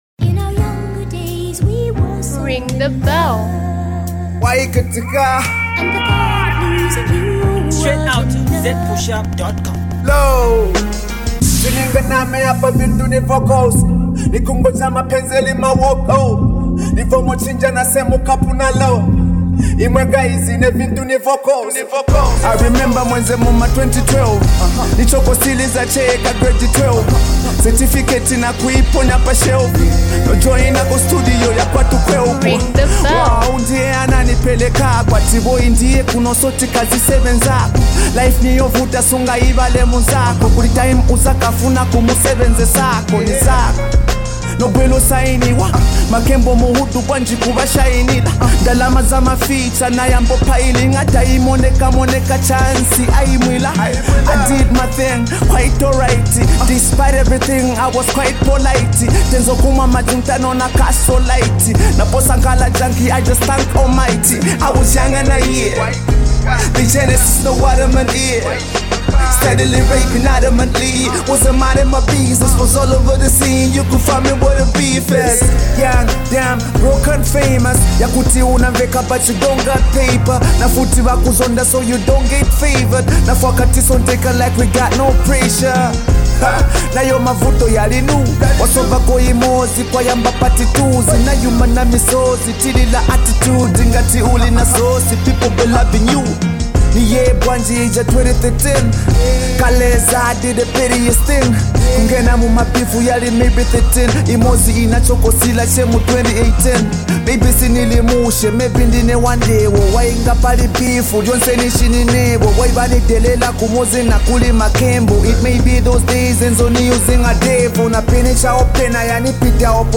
Rap veteran
sick rap up
Download it and enjoy some fresh bars.